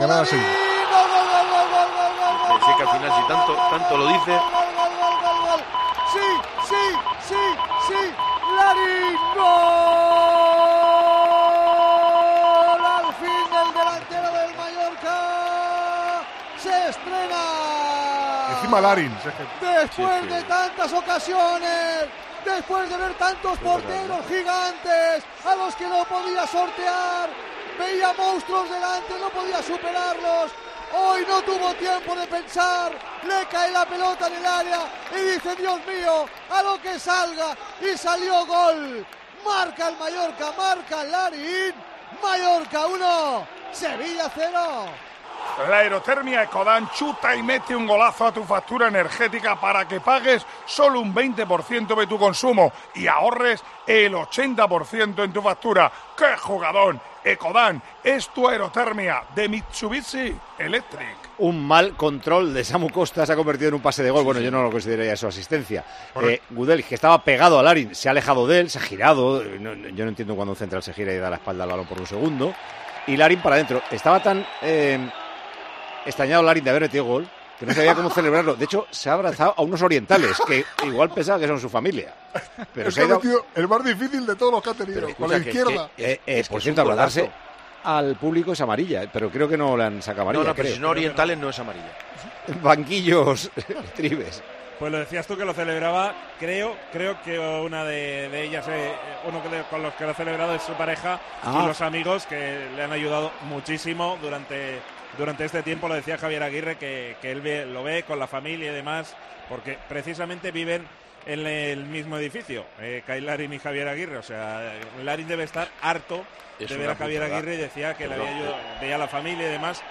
Tiempo de Juego en el Estadio de Son Moix.